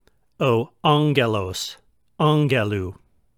Pronunciation Note: When there is a double Gamma (ɣɣ) in a word, such as in ἄɣɣελος, the first Gamma is pronounced like the ng in sing.